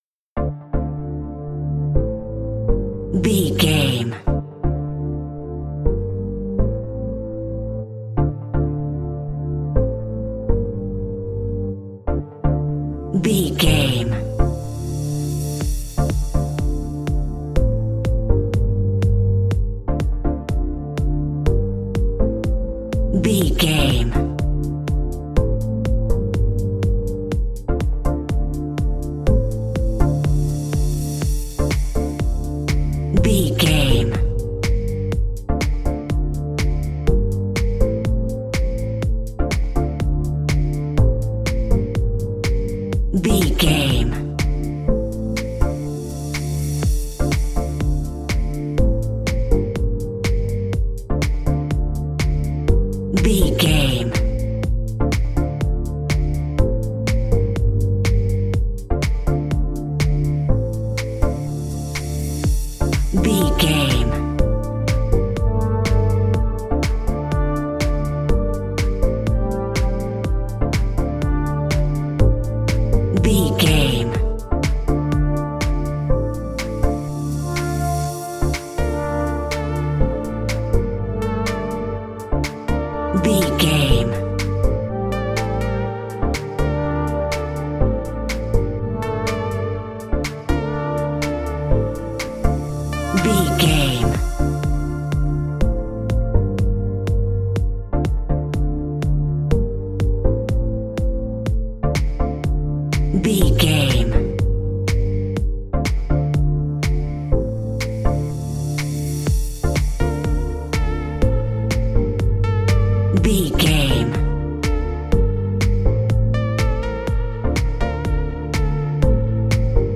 Aeolian/Minor
D
groovy
uplifting
driving
energetic
repetitive
synthesiser
drum machine
electric piano
techno
trance
synthwave
synth leads
synth bass